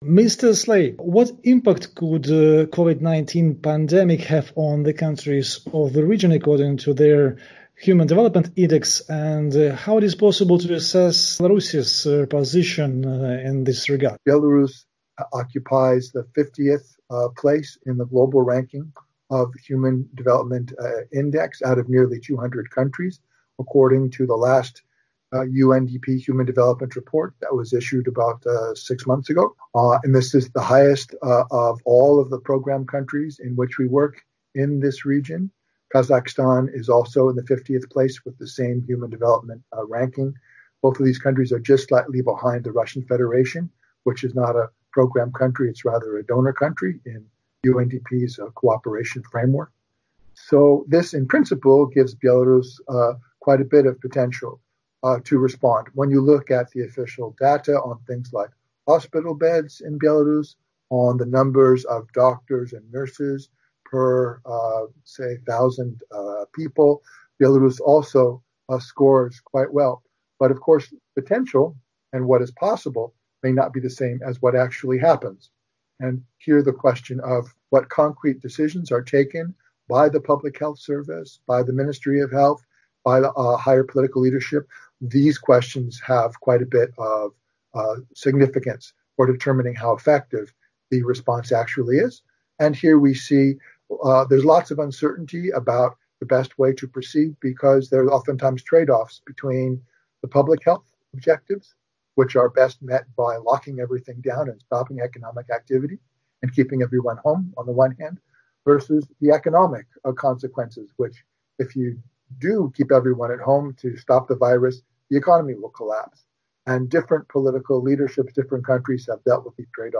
UNDP official on pandemic's impact and response to COVID-19 (interview)